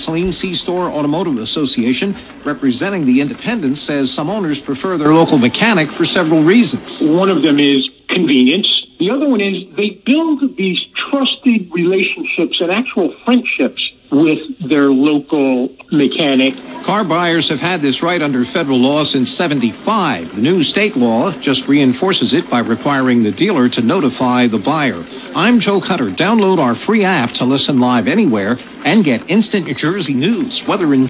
The bill makes it illegal for motor vehicle manufacturers to void a warranty or deny coverage because an aftermarket service provider used a recycled part or performed a service on a vehicle. The story was picked up on 101.5 this week, if you want to listen, click